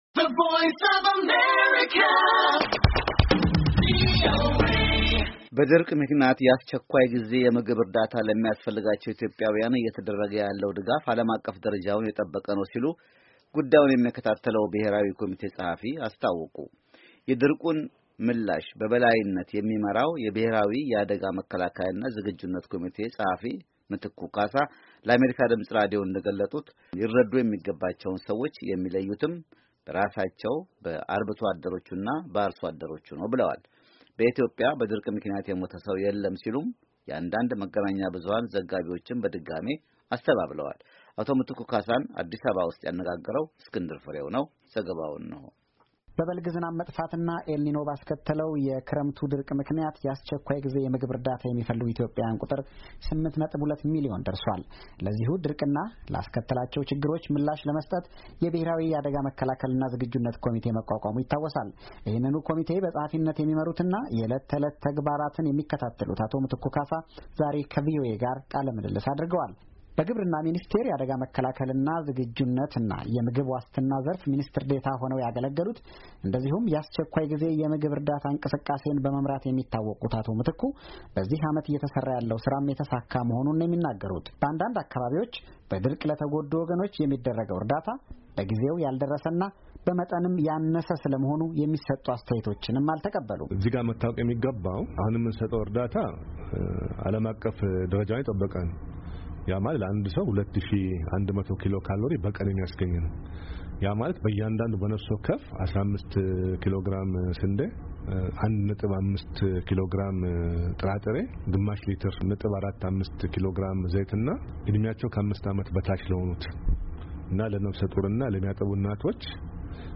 DPPC Secretary Mitiku Kassa speaks about emergency food distribution